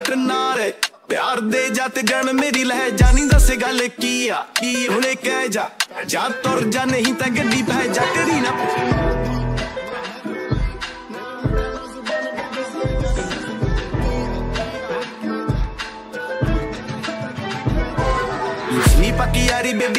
6TypeRelaxing / Soothing Instrumental Tone